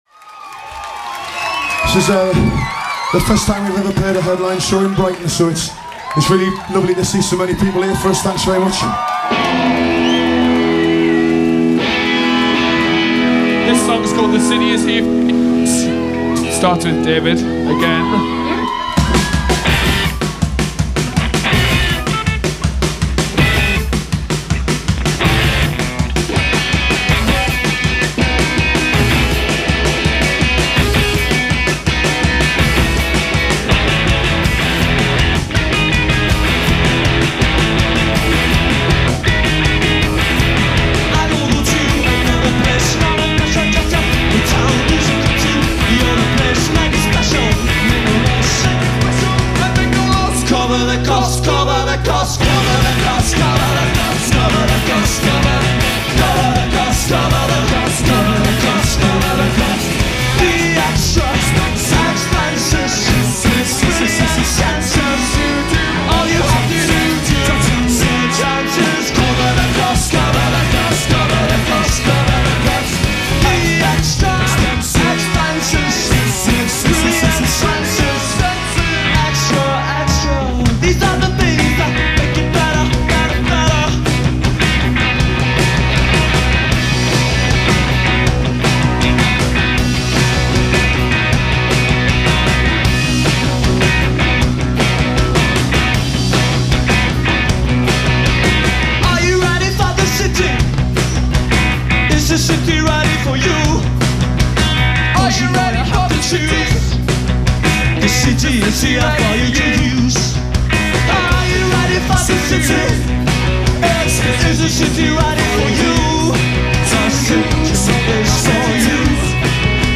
high-energy bands